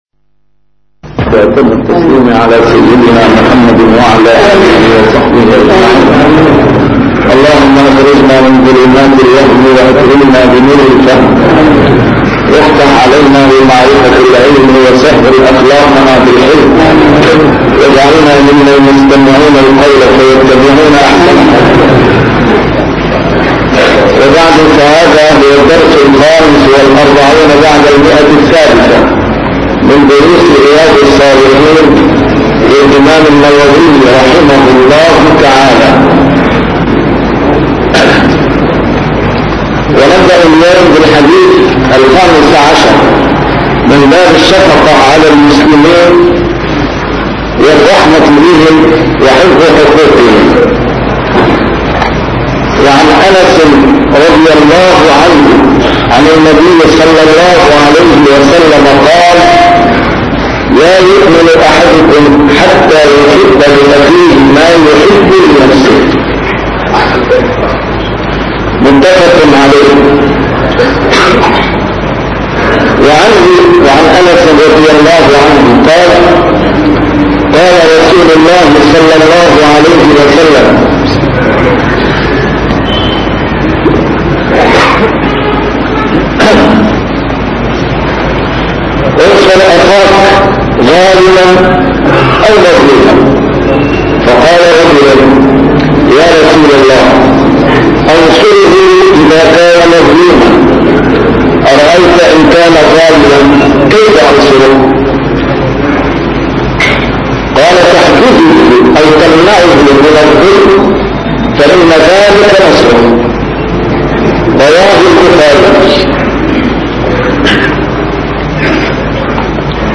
A MARTYR SCHOLAR: IMAM MUHAMMAD SAEED RAMADAN AL-BOUTI - الدروس العلمية - شرح كتاب رياض الصالحين - 345- شرح رياض الصالحين: تعظيم حرمات المسلمين